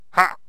stickfighter_attack10.wav